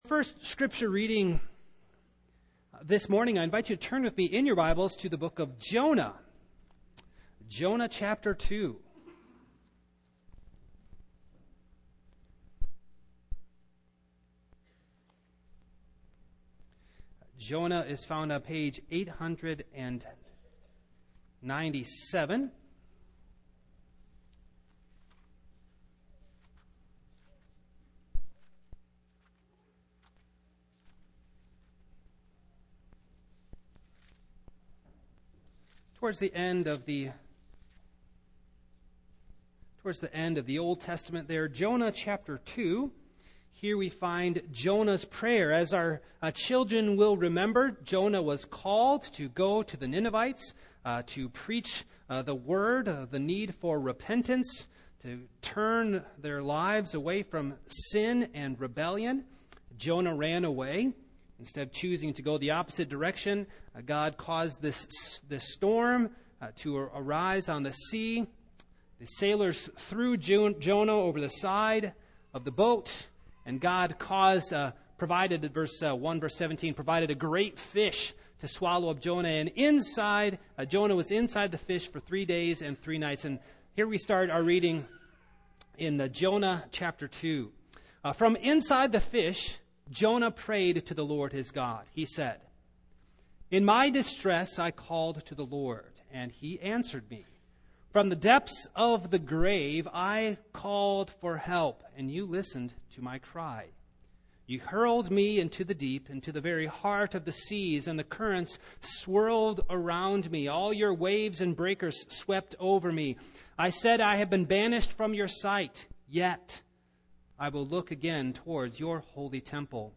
Single Sermons
Service Type: Morning